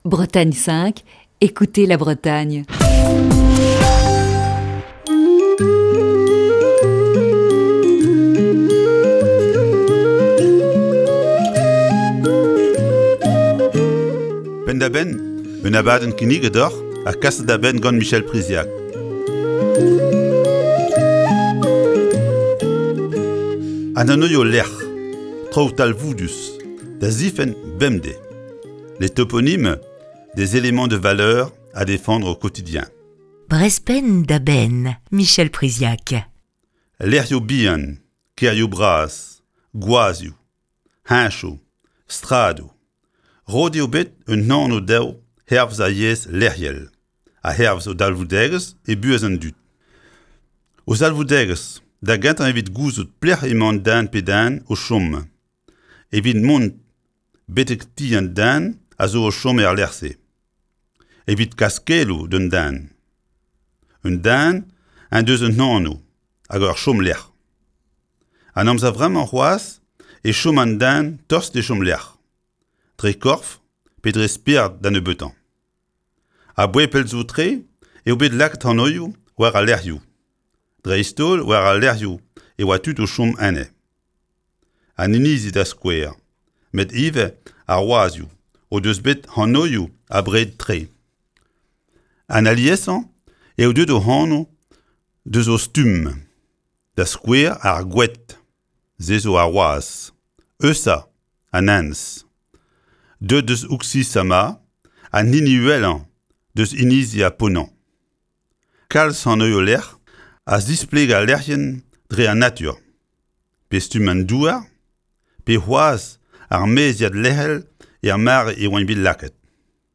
Chronique du 7 août 2020.